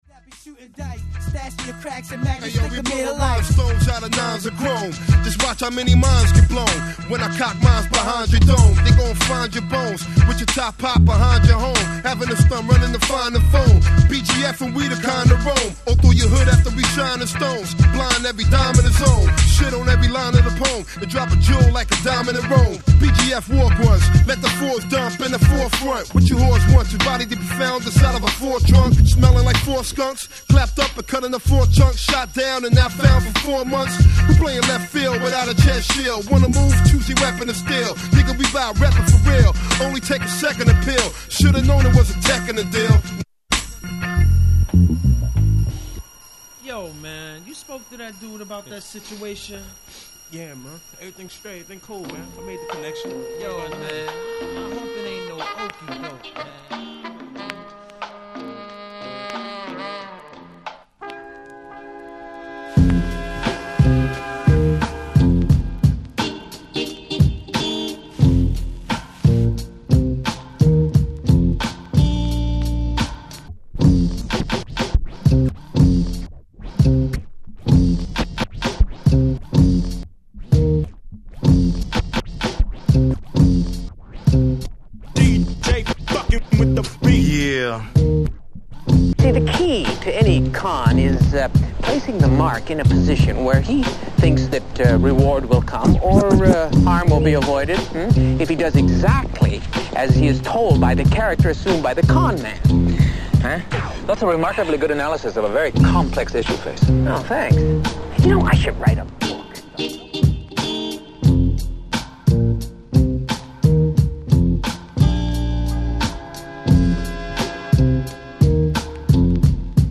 Another multitracked excursion